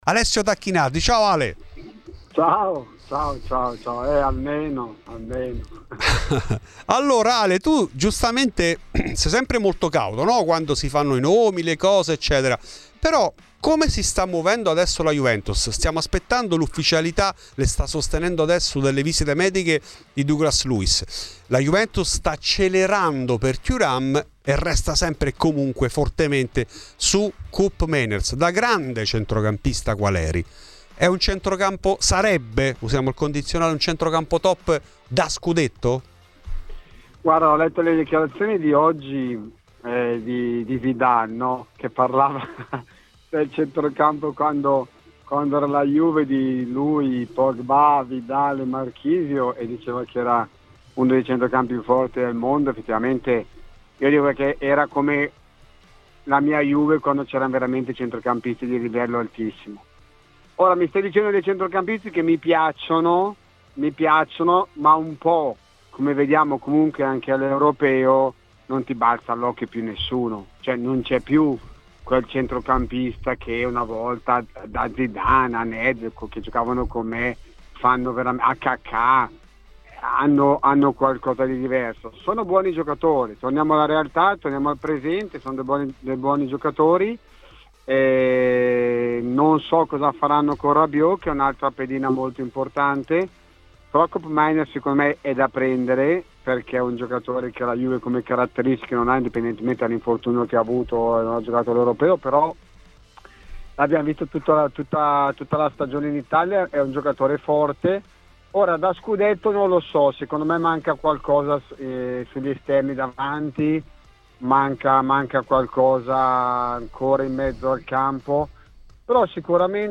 Di questi ed altri temi ha parlato in ESCLUSIVA a Fuori di Juve Alessio Tacchinardi.